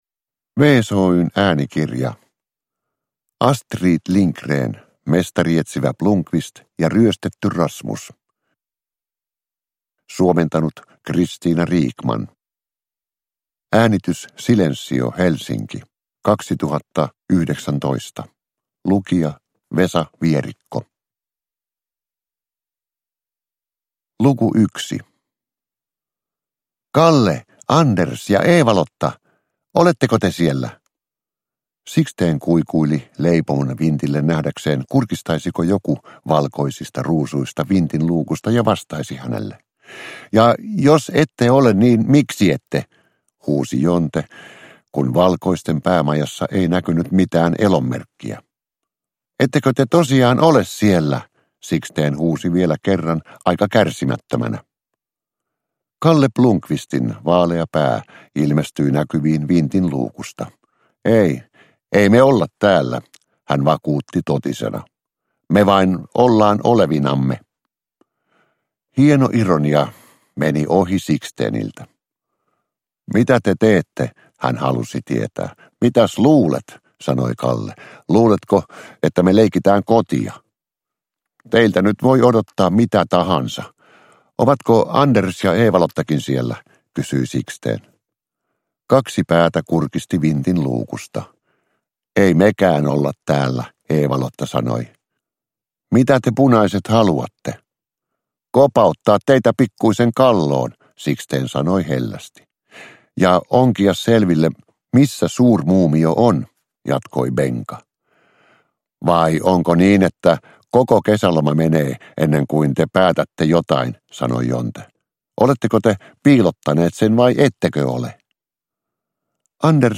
Mestarietsivä Blomkvist ja ryöstetty Rasmus – Ljudbok – Laddas ner
Uppläsare: Vesa Vierikko